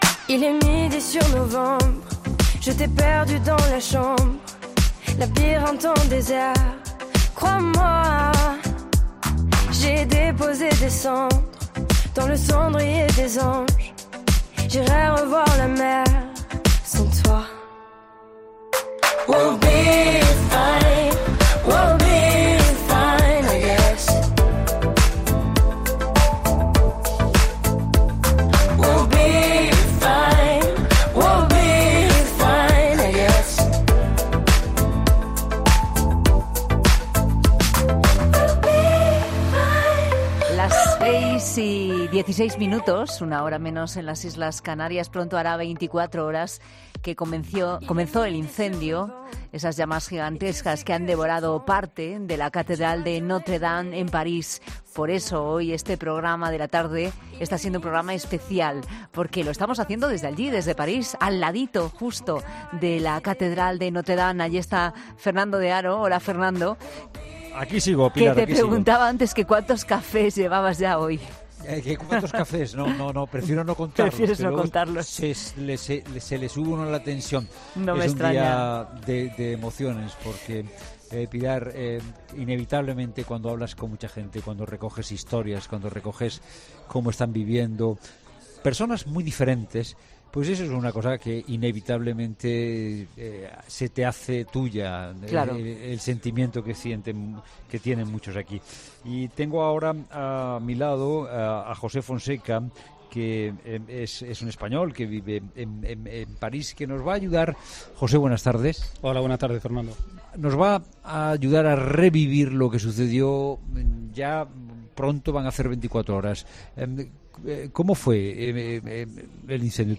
En La Tarde hemos hablado desde París con personas que presenciaron el fuego que este lunes destruyó gran parte de la emblemática catedral francesa